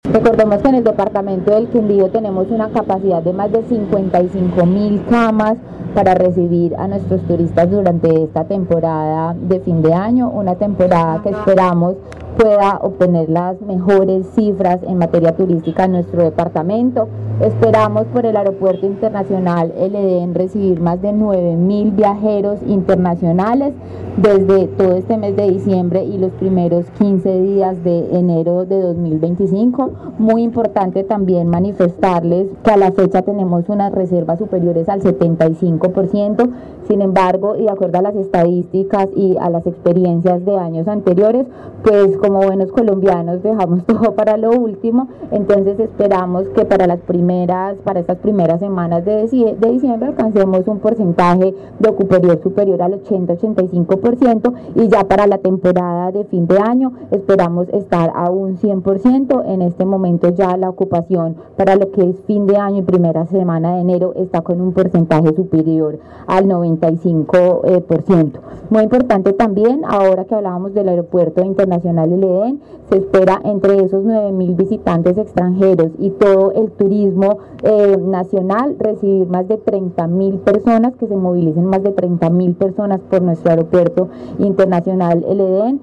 Juana Gómez, secretaria de Turismo